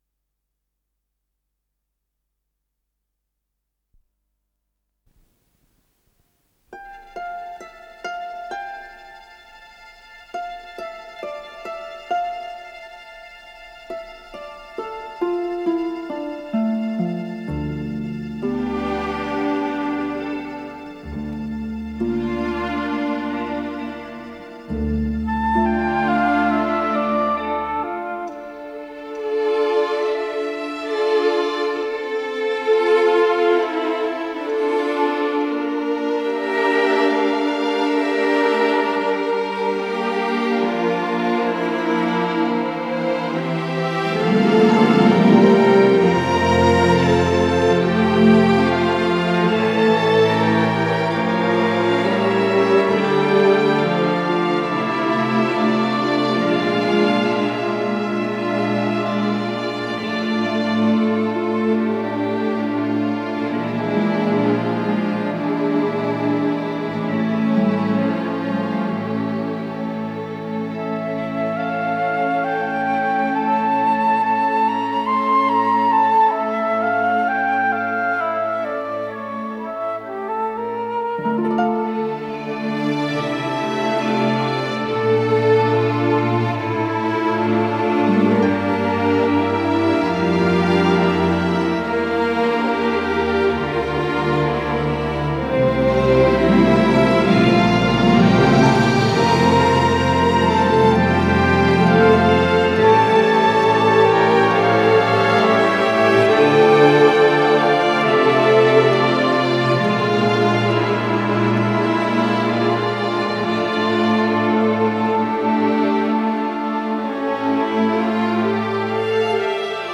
с профессиональной магнитной ленты
ре минор